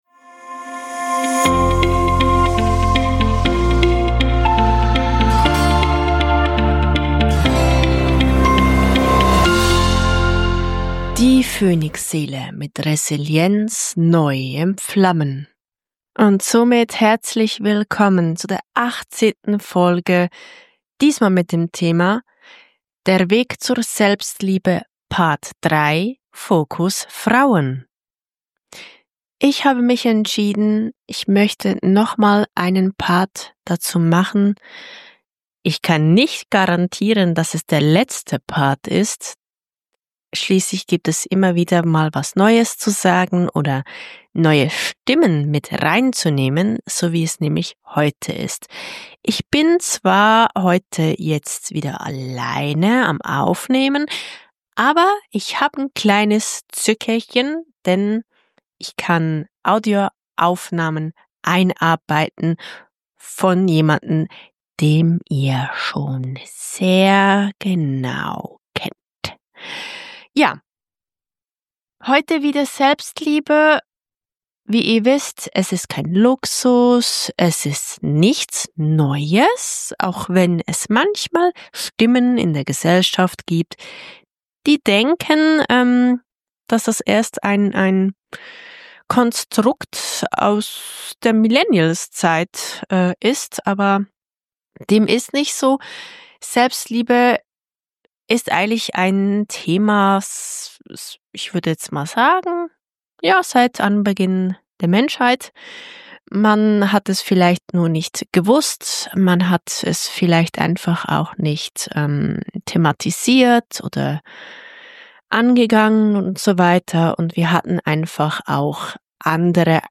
In dieser Episode spreche ich über das Thema Selbstliebe Fokus Frauen und wie ich den Barbie-Film und mit seiner Botschaft damit verbinde. Vier unterschiedliche Frauen teilen ihre persönlichen Gedanken dazu – teils mit eigener Stimme, teils von mir vorgelesen.